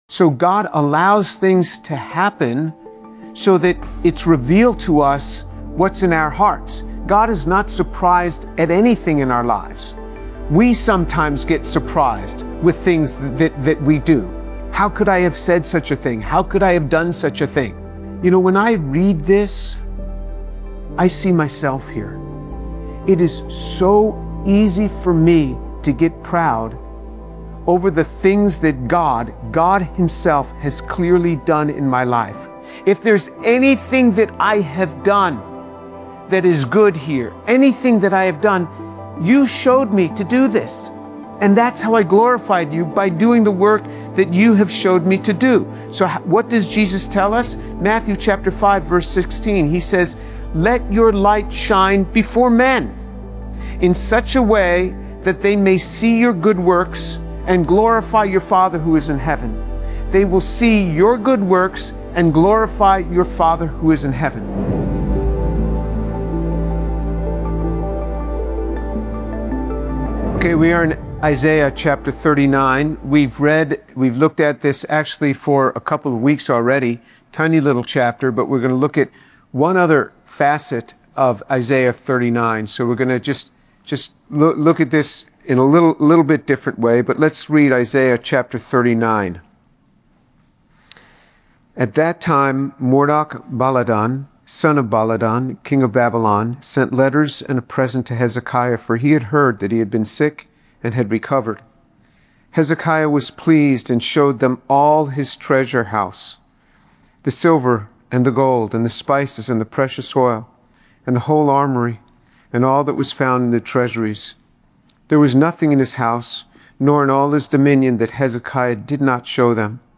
In this sermon, Dr. James Tour warns of the subtle danger of pride—especially when we boast from things God has done—and uses Hezekiah’s story in Isaiah chapter 39 and 2 Chronicles to show how God tests hearts and calls for humility.